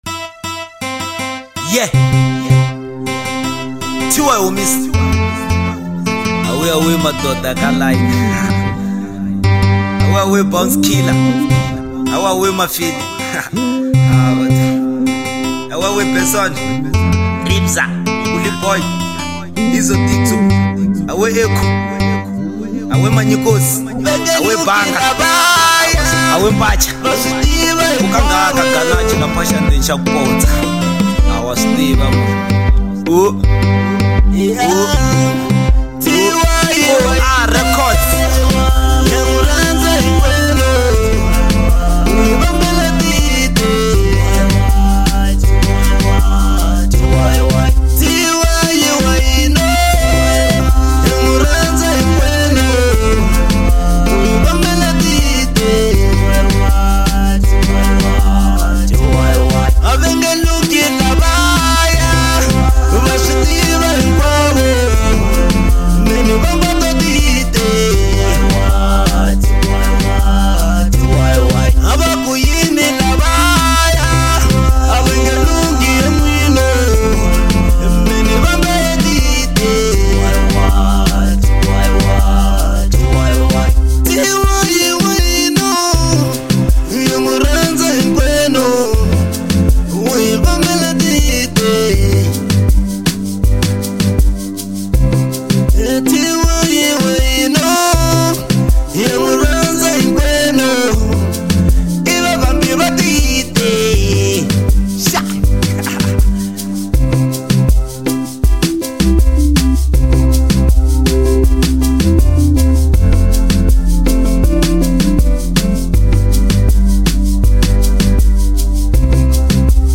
05:25 Genre : Afro Pop Size